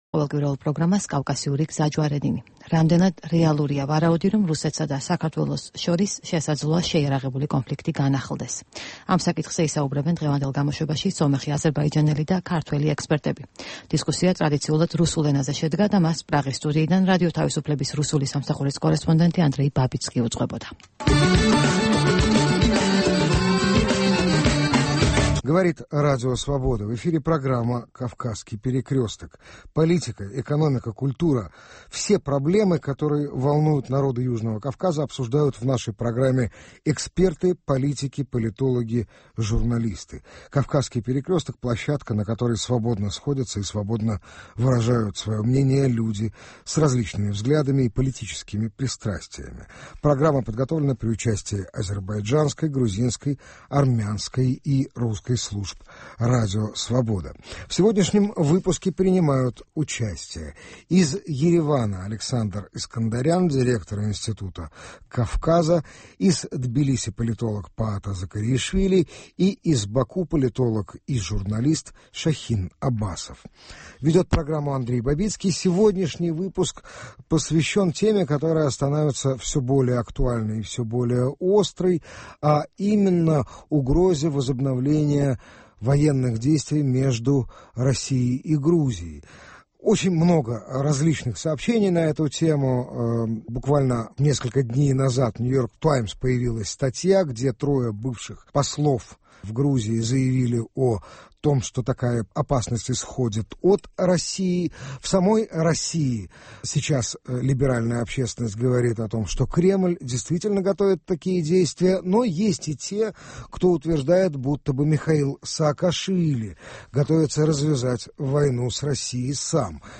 რამდენად გამართლებულია ვარაუდი, რომ საქართველოსა და რუსეთს შორის შეიარაღებული კონფლიქტი შეიძლება განახლდეს? ამ თემაზე მსჯელობენ რადიო თავისუფლების ქართული, სომხური, აზერბაიჯანული და რუსული რედაქციების ერთობლივ პროგრამაში.